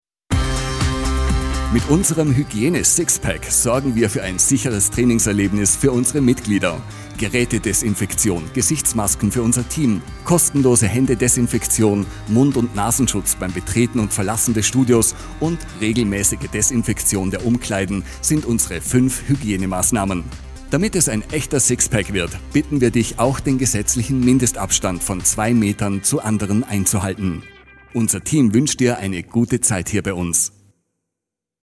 3.-SIXPACK-HYGIENE-DURCHSAGE-2-Meter.mp3